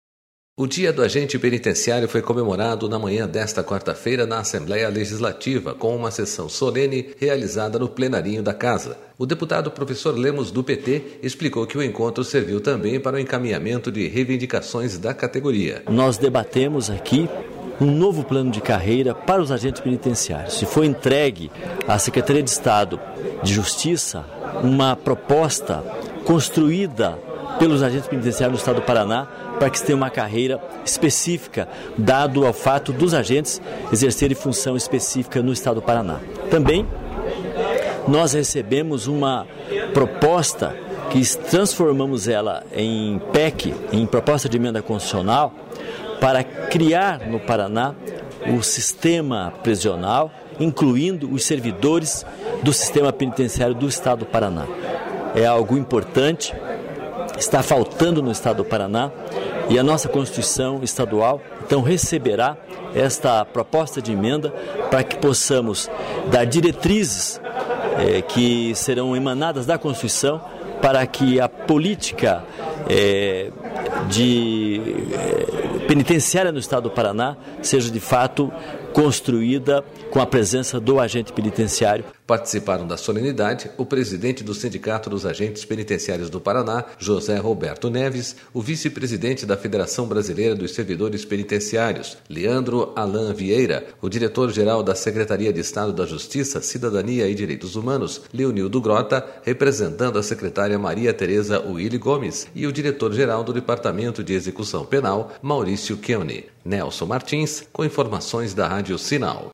O Dia do Agente Penitenciário foi comemorado na manhã desta quarta-feira pela Assembleia Legislativa, com uma sessão solene realizada no Plenarinho da Casa.//O deputado Professor Lemos, do PT, explicou que o encontro serviu também para o encaminhamento de reivindicações da categoria.//SONORA LEMOSPa...